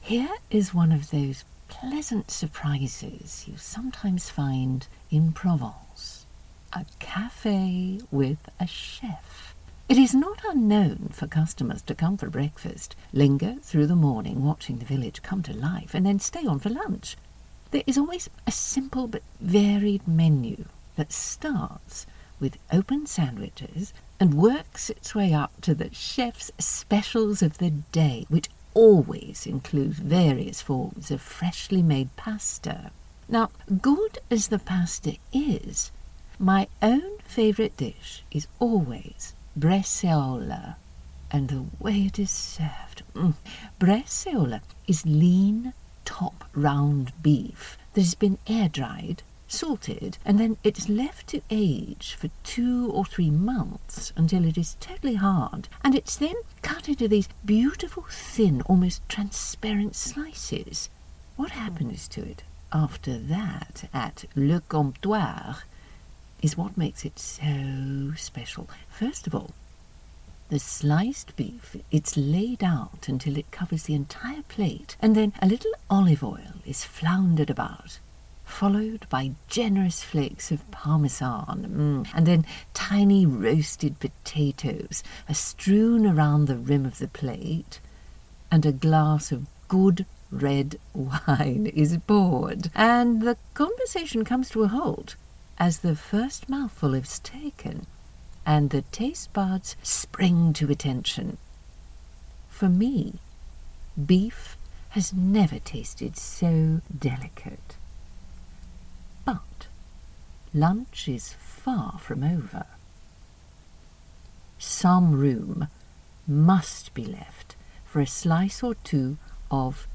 Female
Character / Cartoon
Upper English Accent/American
Words that describe my voice are sensual, conversational, authoritative.
All our voice actors have professional broadcast quality recording studios.